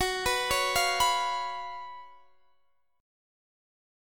Listen to F#M7sus4 strummed